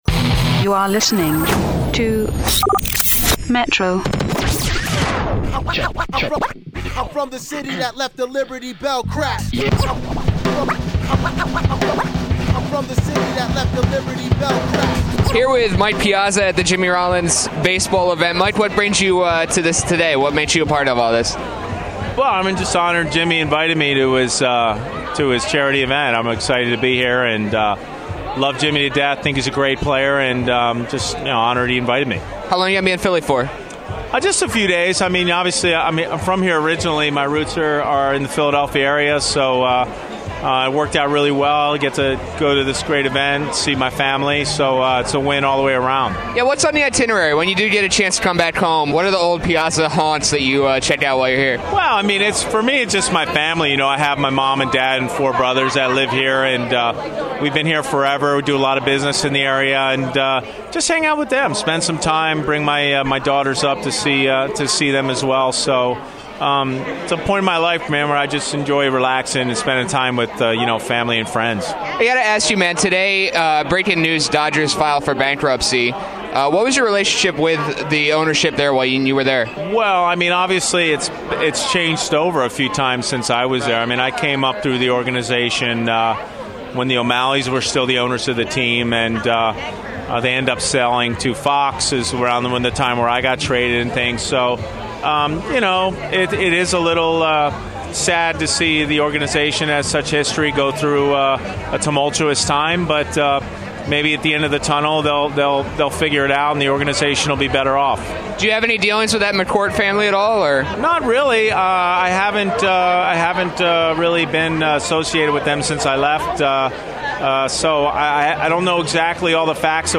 Today at Lucky Strike in Philadelphia, Phillies star Jimmy Rollins held his annual Basebowl charity event.